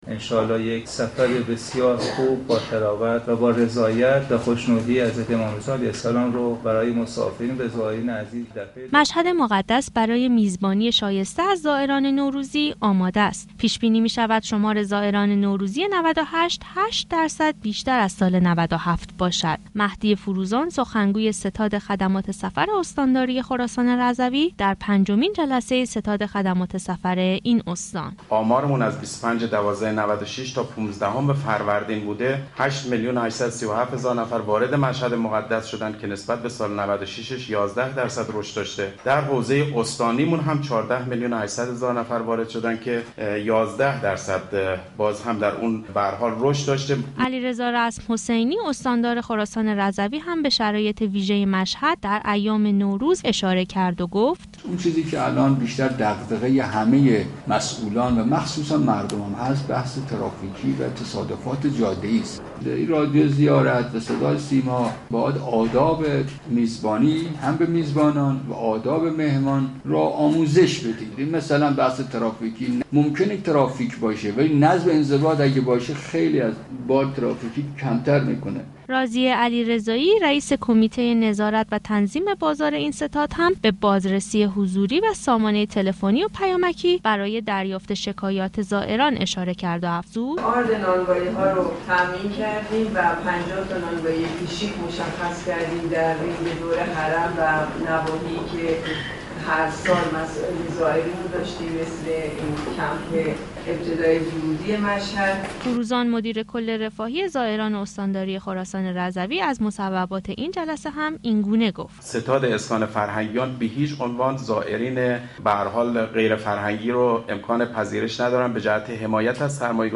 علیرضا رزم حسینی استاندار خراسان رضوی در پنجمین جلسه ستاد هماهنگی خدمات سفر خراسان رضوی گفت:دستگاه های اجرایی برای خدمت رسانی شایسته به زائران و مسافران در ایام تعطیلات نوروز 98 آمادگی دارند.